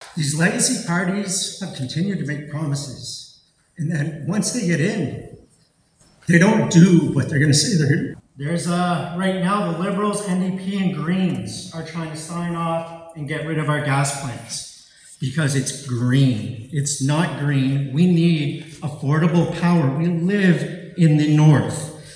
Four candidates appeared at a forum last Tuesday, hosted by the St. Thomas & District Chamber of Commerce.